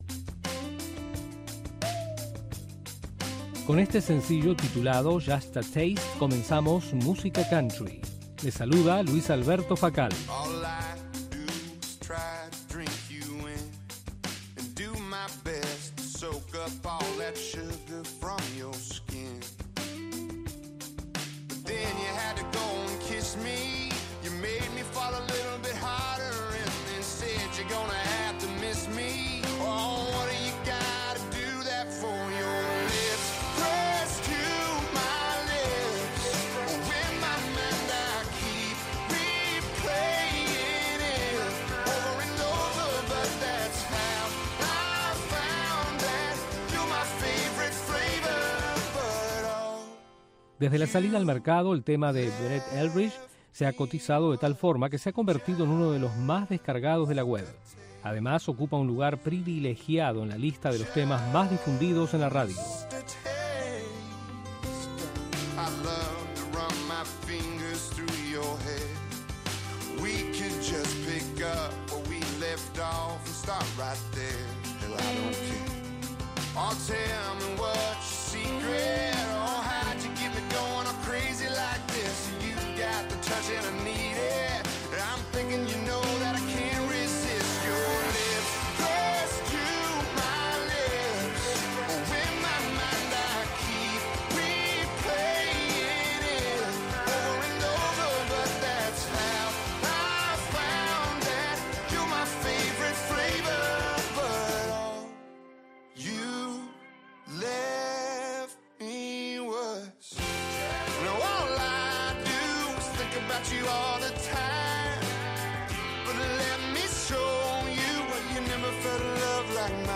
Música Country
Los artistas de la música country se dan cita en este espacio para exponer sus éxitos y compartir algunas noticias de este genero.